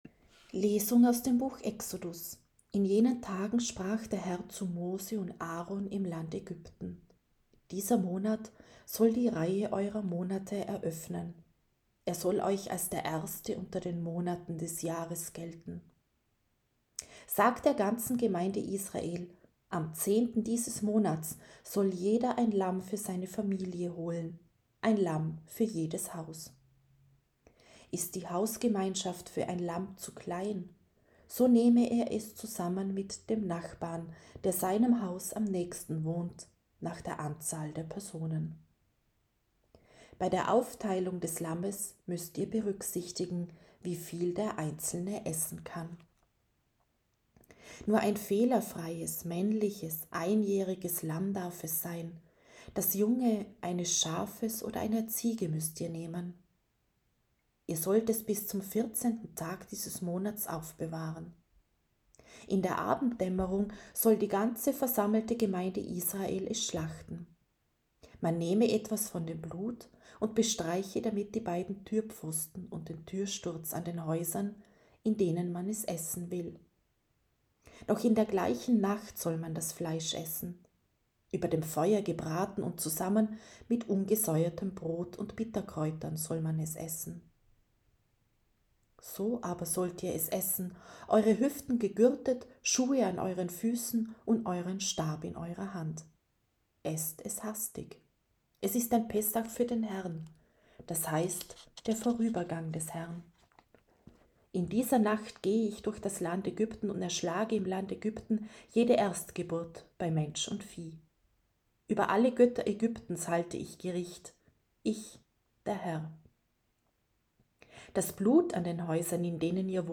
C-Gründonnerstag-1.-Lesung.mp3